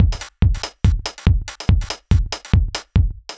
Index of /musicradar/uk-garage-samples/142bpm Lines n Loops/Beats
GA_BeatA142-07.wav